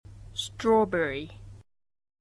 イギリスの発音 - 果物Part 2